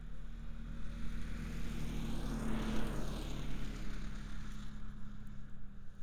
IC Snowmobile Description Form (PDF)
IC Subjective Noise Event Audio File - Run 1 (WAV)